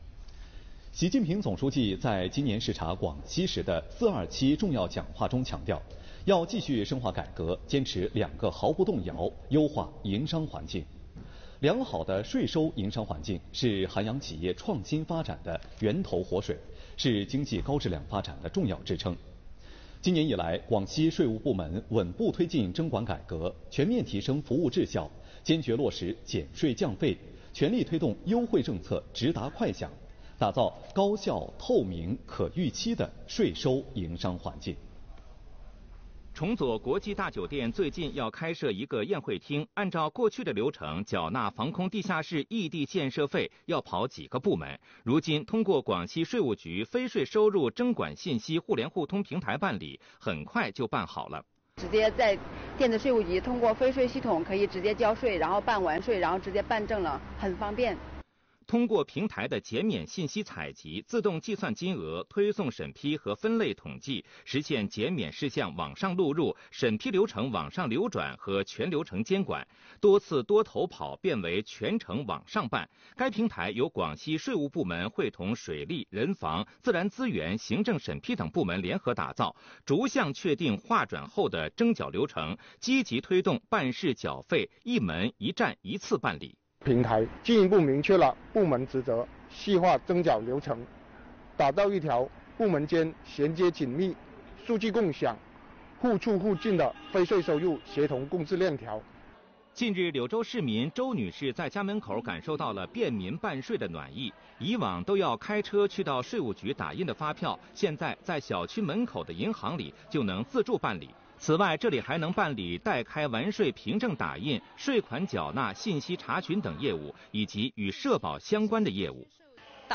电视报道 | 广西：持续优化税收营商环境 助力经济高质量发展